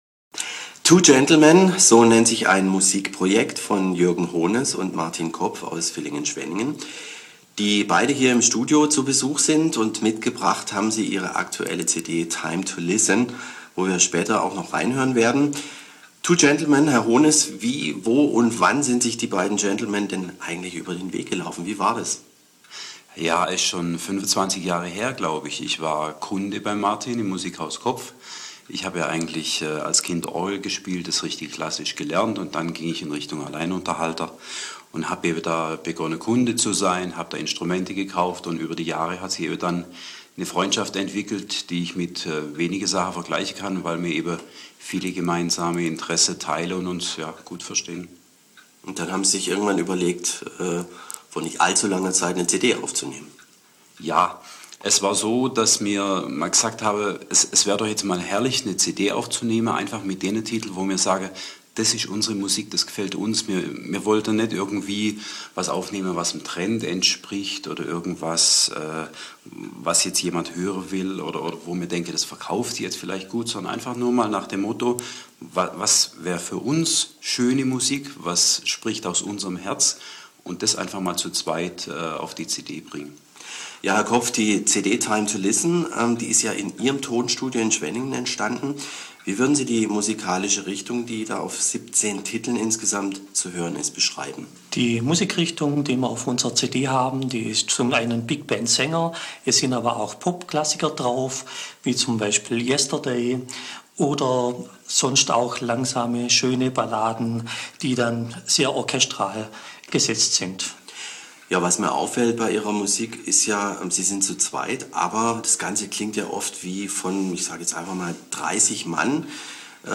Durch klicken auf das Radio Neckarburg Logo gelangen Sie zum Interview vom 12. Mai.